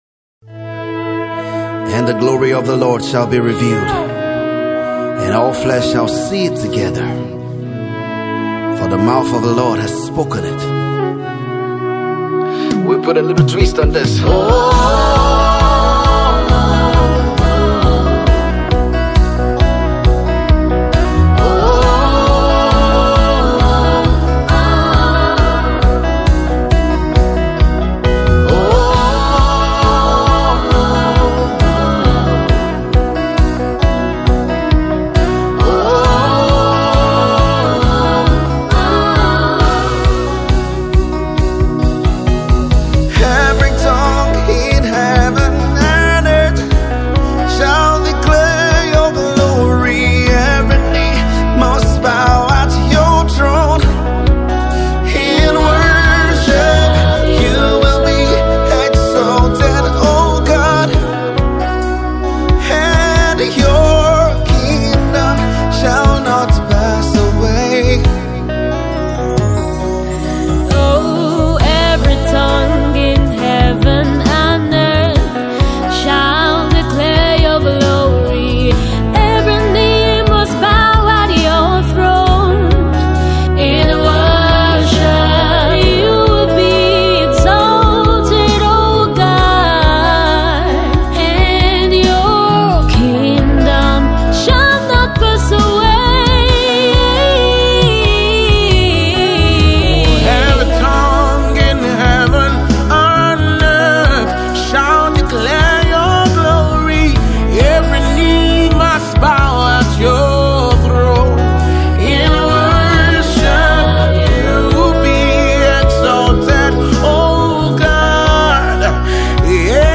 Music minister and gospel recording artiste